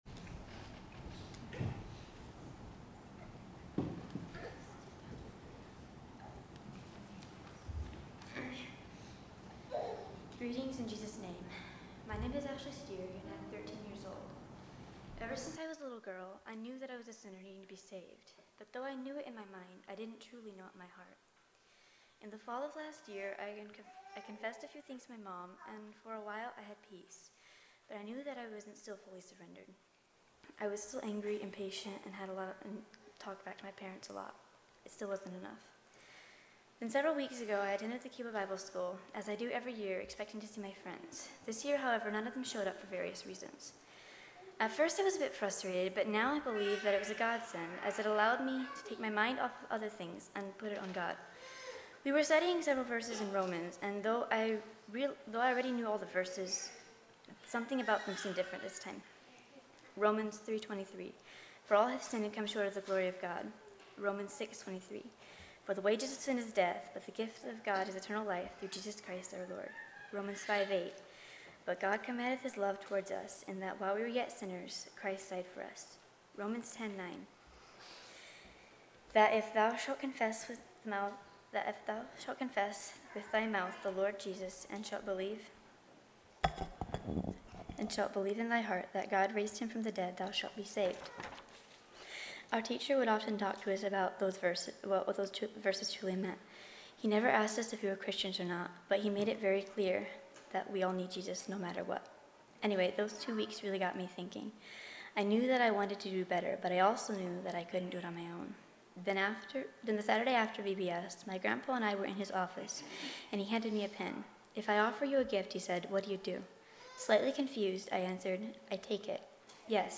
Baptismal Testimony